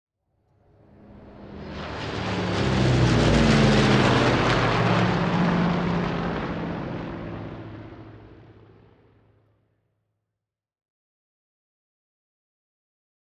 Airplane Duxford flying overhead